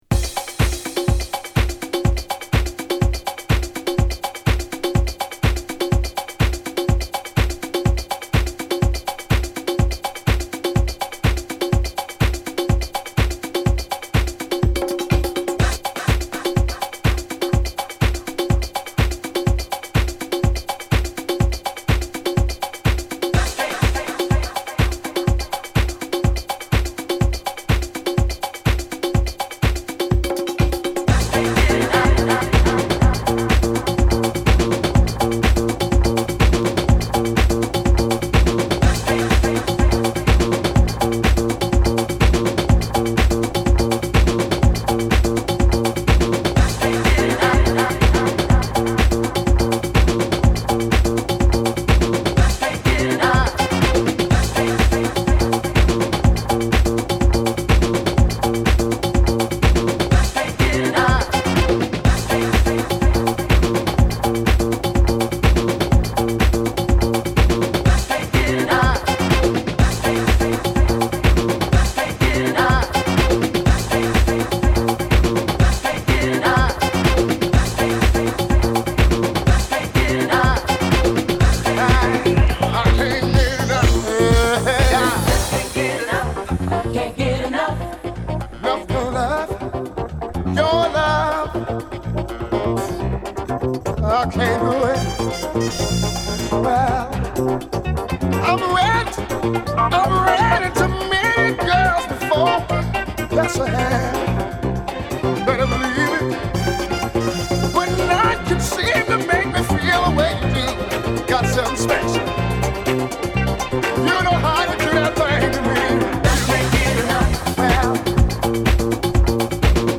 全編に渡りパーカッシヴでダブ・トリップなグルーヴ感にポイントを於いたセンス抜群のエディットを展開させる絶品
Boogie
Re-Edit